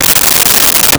Metal Zipper 02
Metal Zipper 02.wav